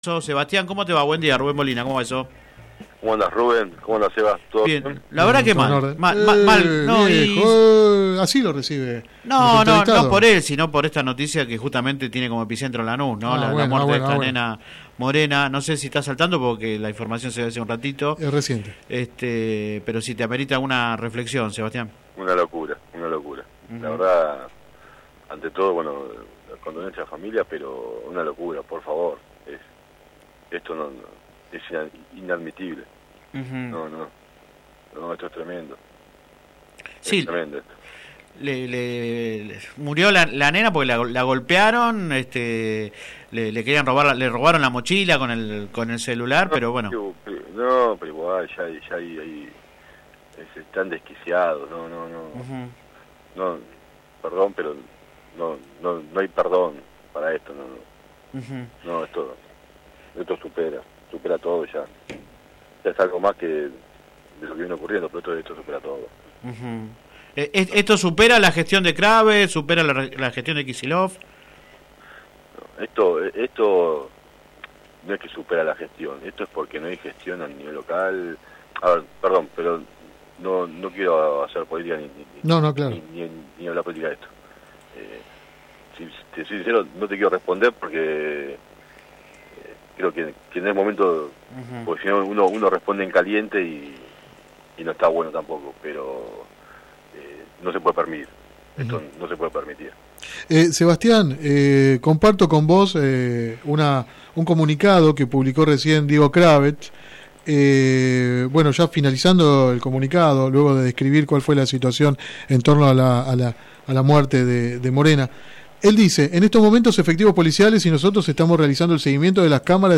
entrevista radial